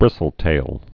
(brĭsəl-tāl)